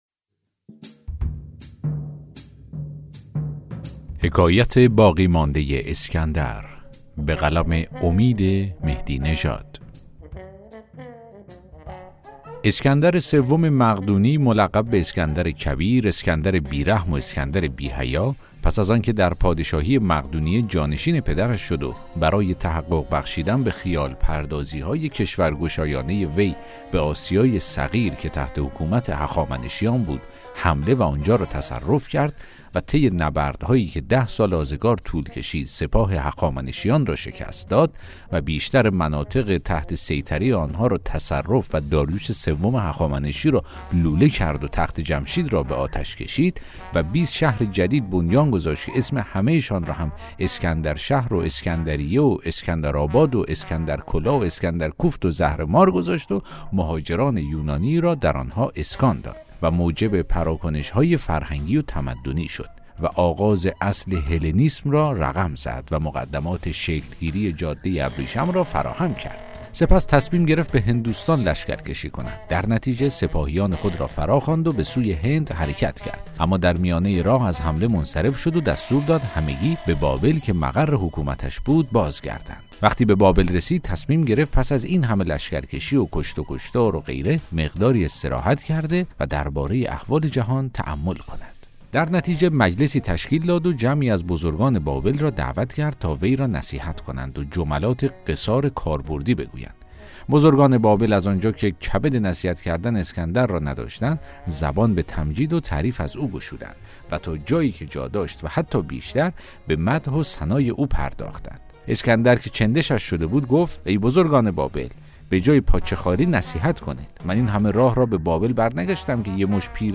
داستان صوتی: حکایت باقی مانده اسکندر